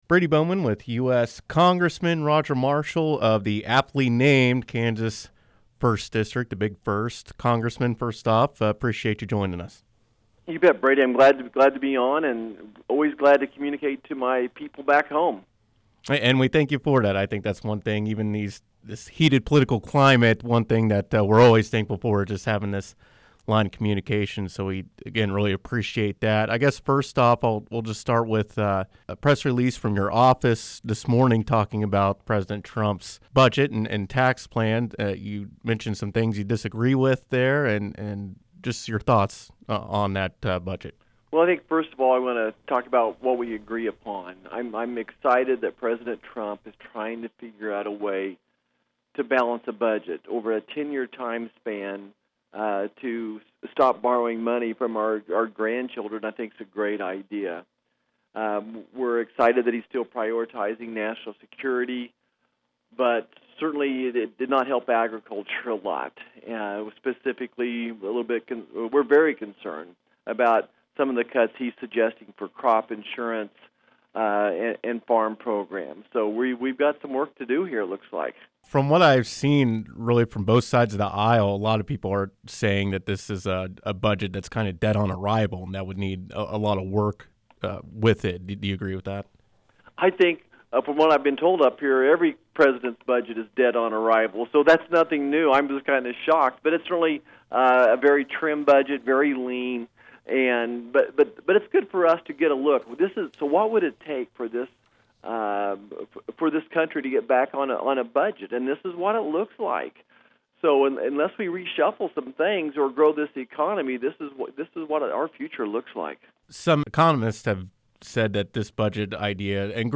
Marshall, who represents Manhattan and Kansas’ 1st District in Washington, spoke with KMAN from the nation’s capitol Tuesday afternoon.